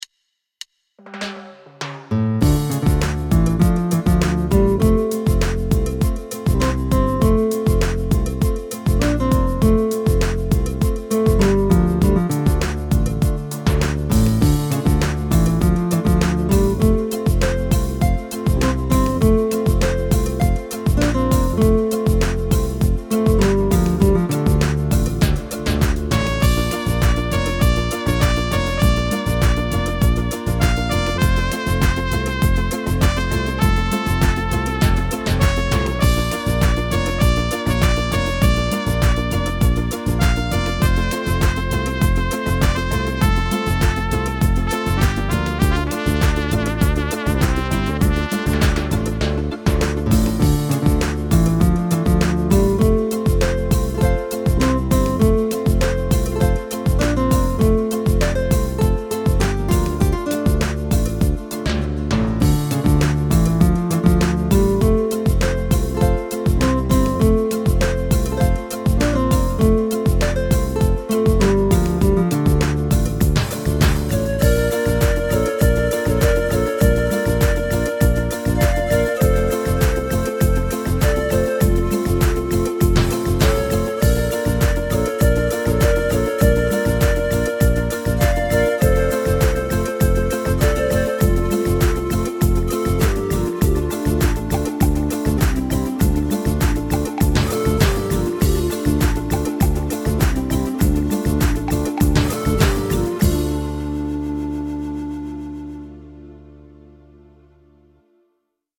Strandparty-Version des Traditionals "El Condor Pasa". Entstanden auf dem PSR-2000 im Jahr 2008.